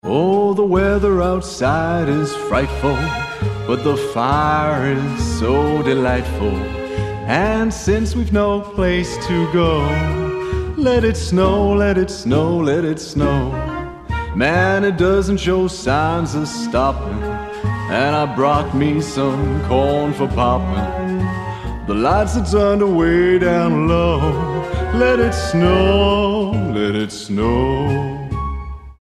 Male
Approachable, Character, Conversational, Corporate, Energetic, Engaging, Friendly, Gravitas, Natural, Reassuring, Sarcastic, Soft, Upbeat, Versatile, Warm
Microphone: Neumann u87